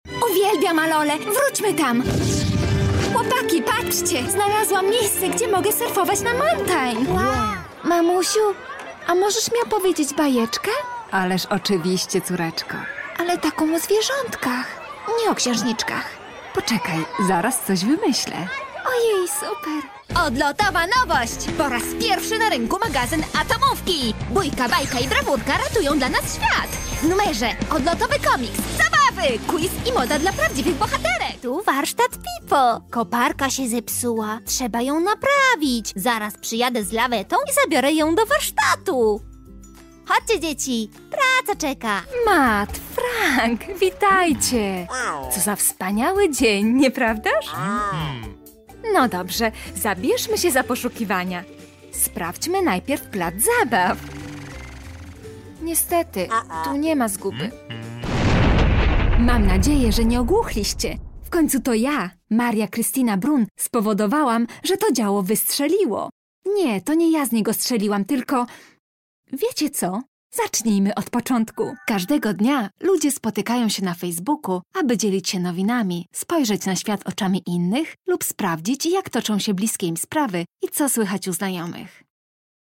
Female
Engaging, Friendly, Natural, Soft, Warm, Versatile
Voice reels
Microphone: Neumann TLM 103, Neumann U87